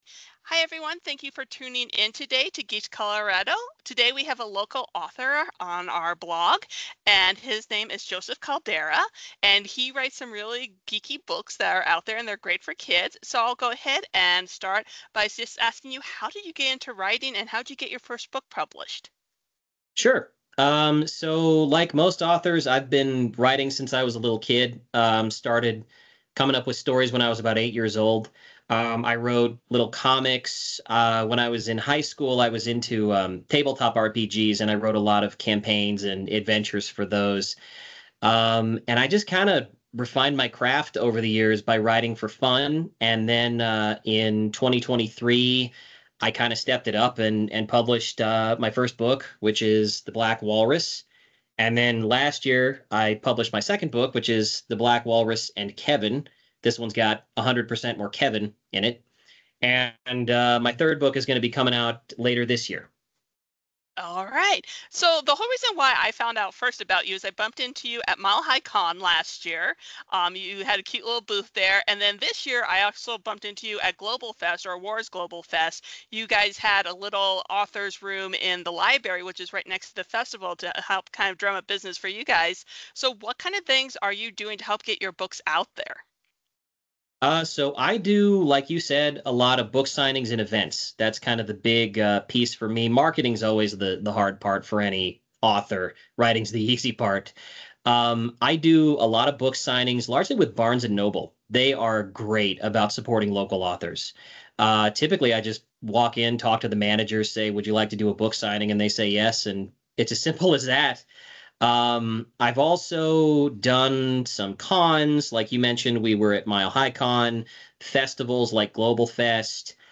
These are kid friendly superhero inspired books. In this interview we not only talk about his books, but also about things like the publishing process for independent authors and how they have to market themselves.